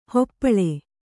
♪ hoppaḷe